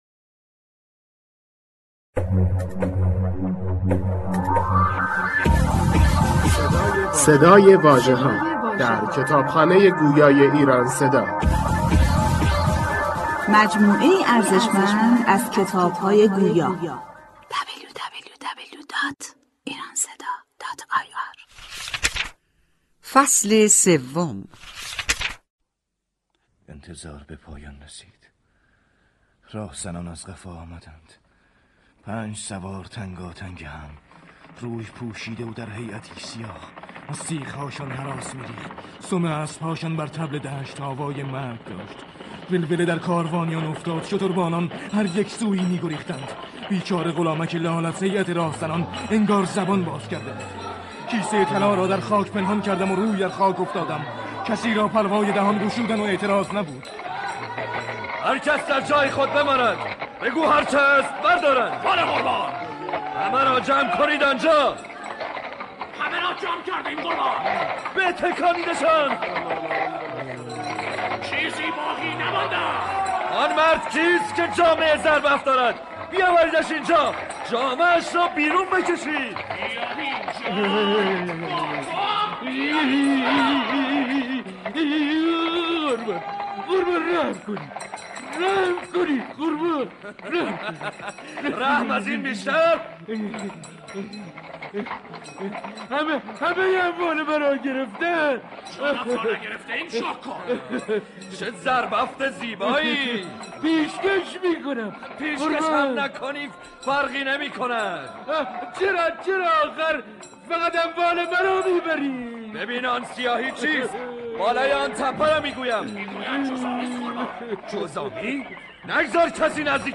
اثری نمایشی با فضاسازی صوتی و بازیگران برجسته.